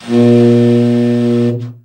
BRA_TEN SFT    2.wav